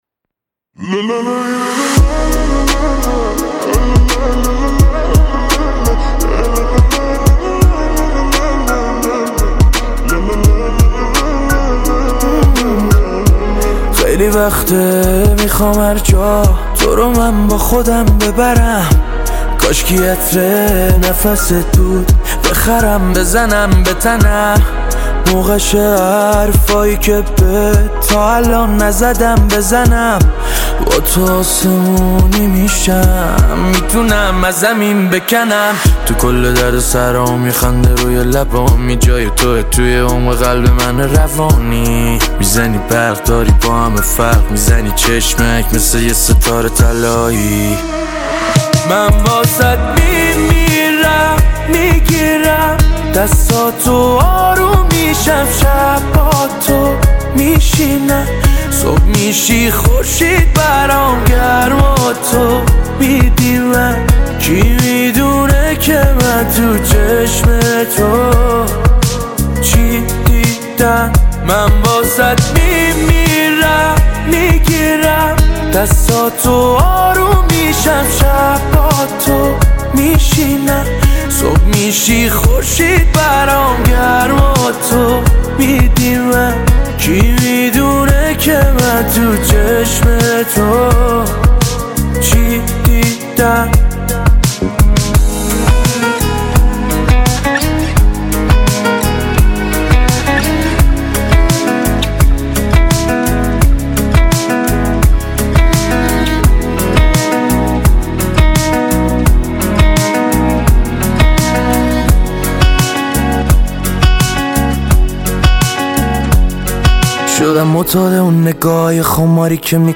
پاپ شاد عاشقانه